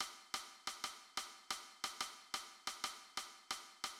The most commonly used key pattern in sub-Saharan Africa is the seven-stroke figure known in ethnomusicology as the standard pattern,[18][19][20] or bembé.[21] The standard pattern is expressed in both a triple-pulse (12
8 bell.[23] The standard pattern has strokes on: 1, 1a, 2& 2a, 3&, 4, 4a.
Standard_pattern_triple.mid.mp3